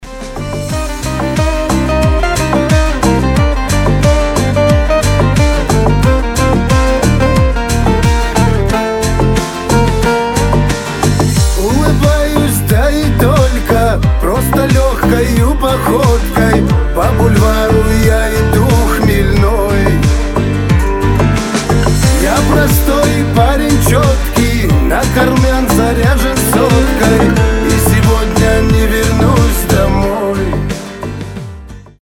• Качество: 320, Stereo
гитара
мужской голос